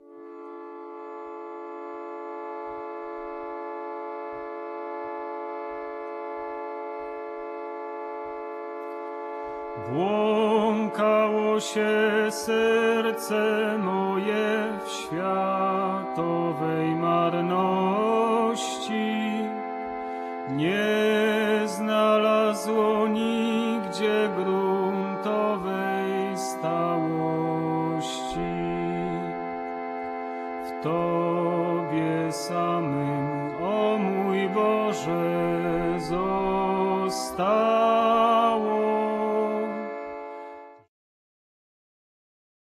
basy bass, śpiew voice